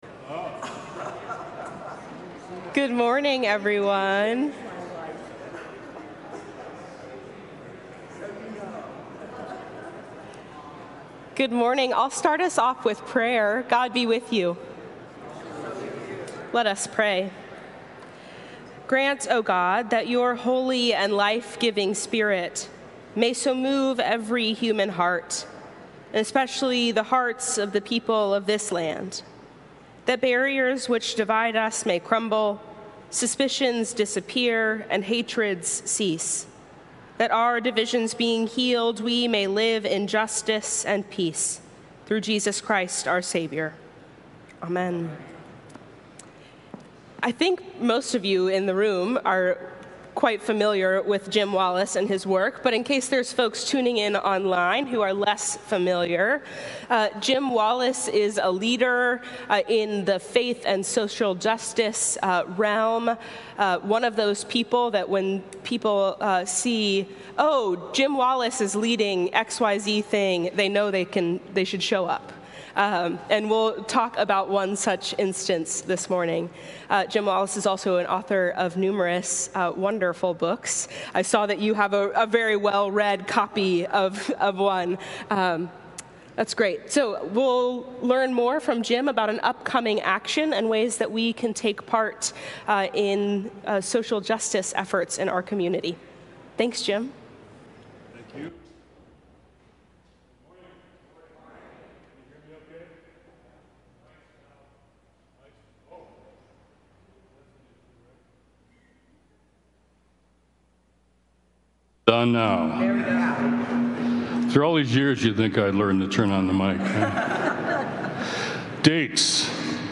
Sunday Forum from St. Columba's in Washington, D.C. Faith in Action: Pentecost Action Opportunity with Jim Wallis May 26 2025 | 00:43:28 1x 00:00 / 00:43:28 Subscribe Share RSS Feed Share Link Embed